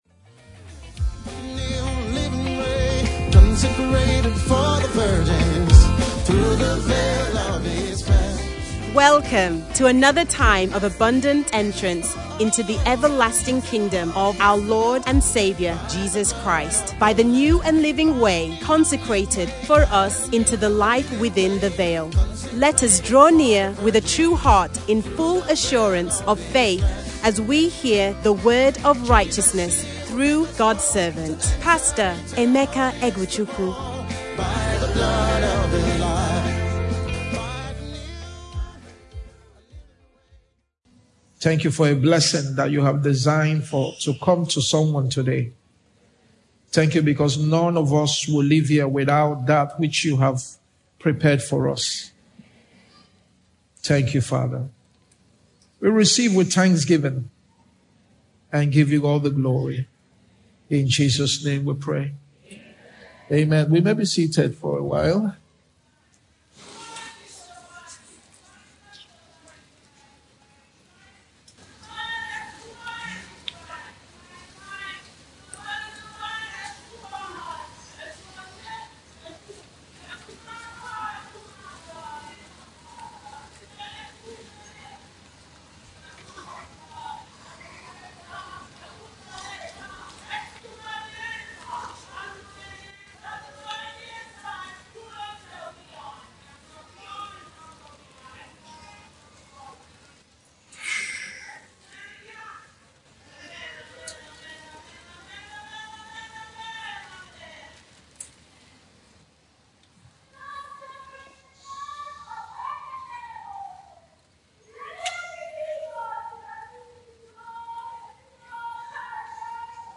From Category: "Sunday Message"